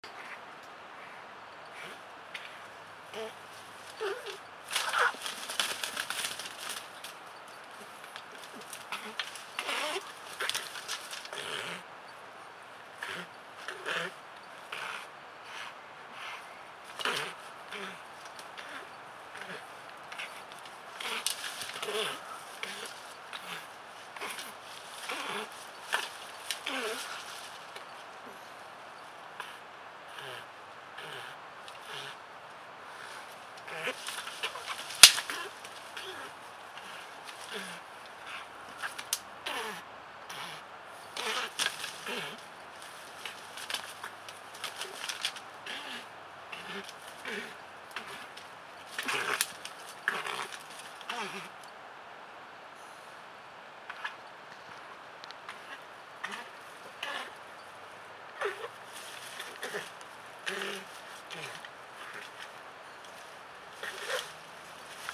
На этой странице собраны разнообразные звуки, издаваемые опоссумами: от защитного шипения до тихих щелчков.
Шум схватки двух опоссумов